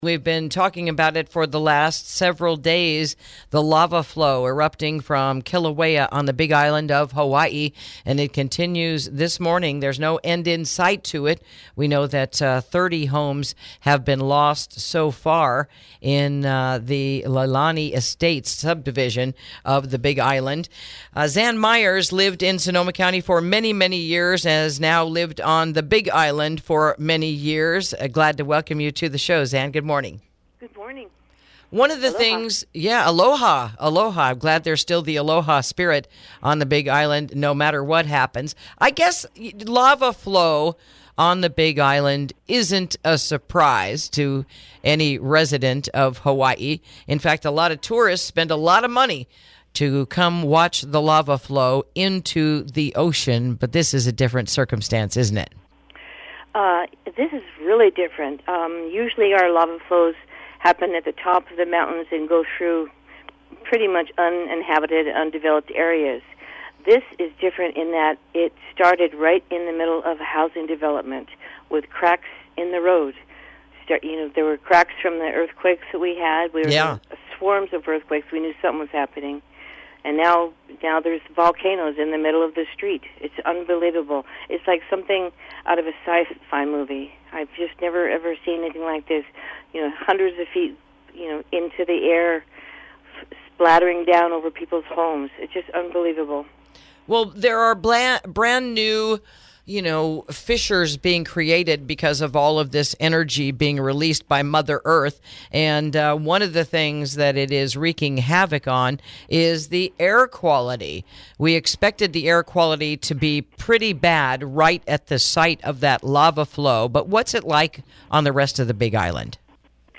Interview: A Vivid Picture of the Aftermath of the Recent Hawaiian Volcano Eruption